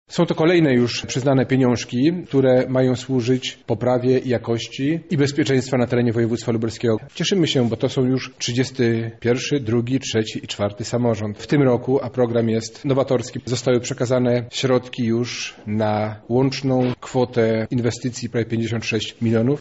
Umowy wręczył przedstawicielom samorządów zastępca wojewody lubelskiego Robert Gmitruczuk: